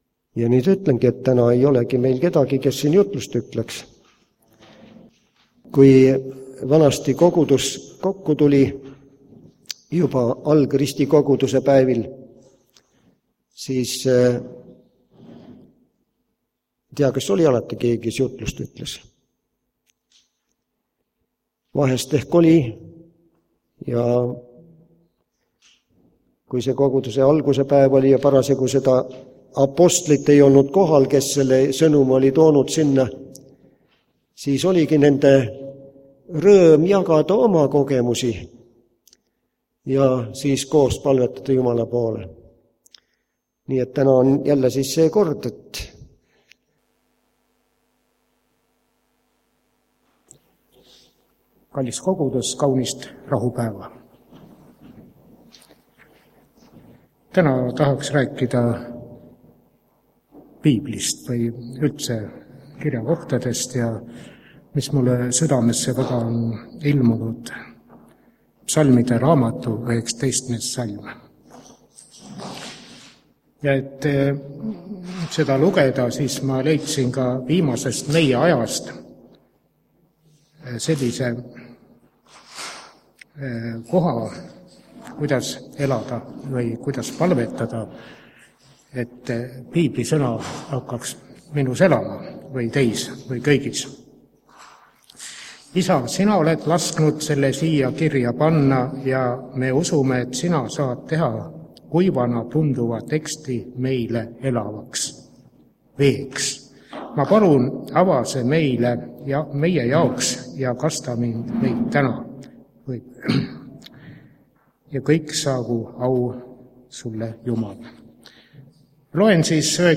Täna on Haapsalu adventkoguduses taas